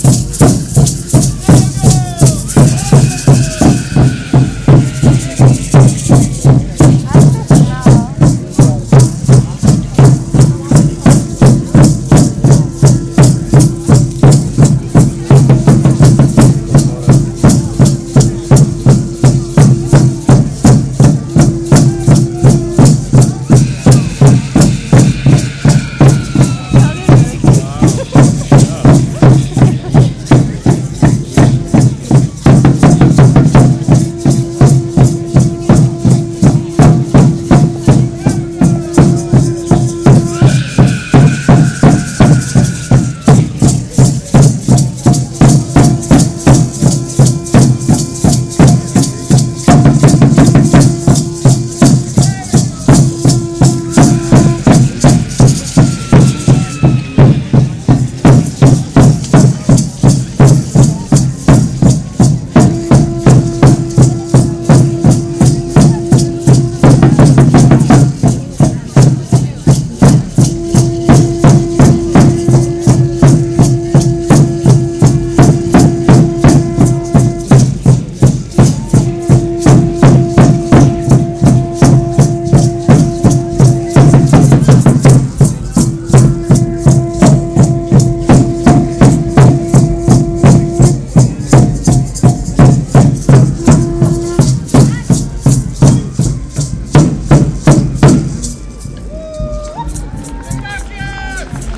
Indian drums
72293-indian-drums.mp3